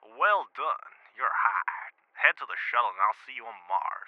Voice Lines
well done youre hired head to the shuttle.wav